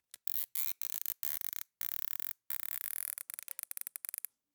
Zip Ties Secure 5 Sound
household